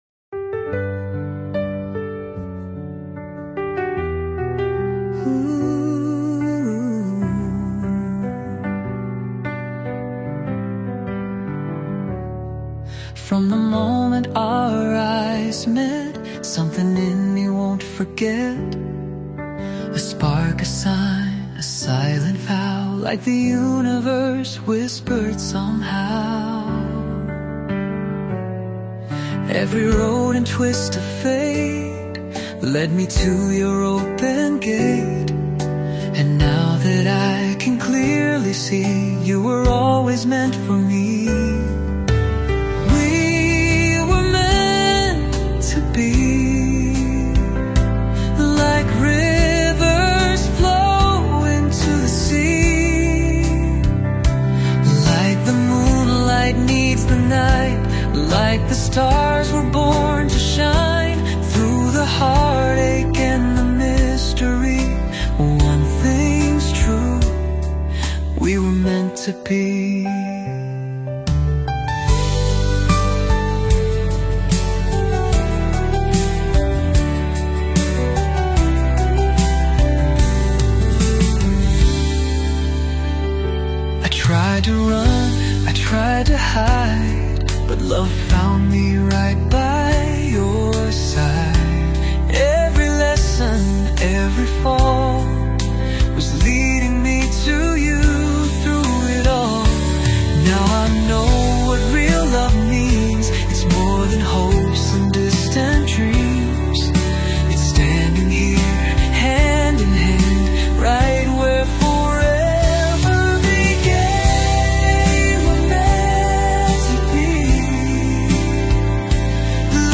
soulful romantic ballad
powerful, clear vocals
• Genre: Romantic Pop / Soul Ballad